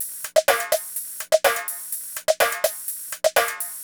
TechnoFunky-44S.wav